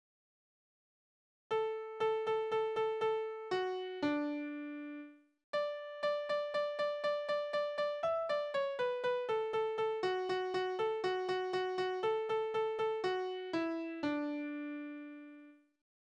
Tonart: D-Dur
Taktart: (4/4)
Tonumfang: große None
Besetzung: vokal
Anmerkung: Vortragsbezeichung: ziemlich schnell Keine Tonart vorgezeichnet